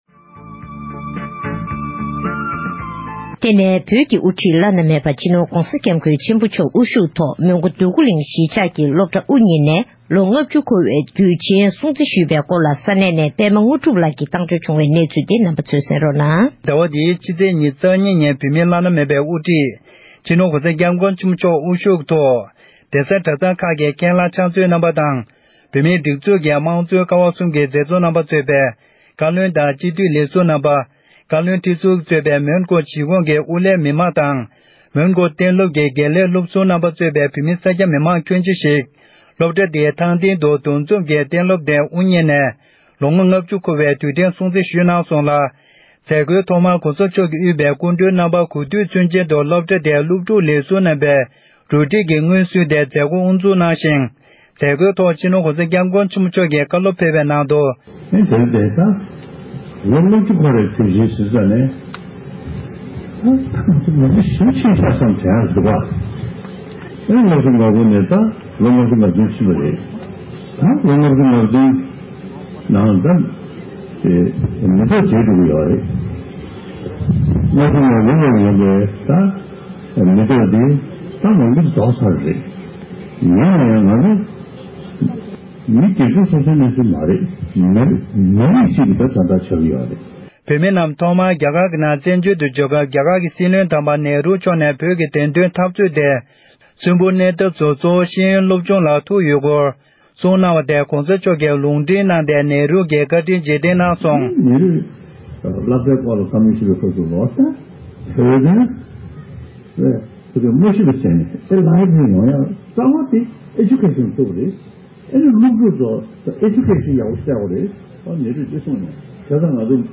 * ཞིབ་ཕྲ་འདི་གའི་གསར་འགོད་པས་ས་གནས་ནས་བཏང་བའི་གནས་ཚུལ་གོང་གི་དྲ་ཐག་ནས་གསན་ཐུབ།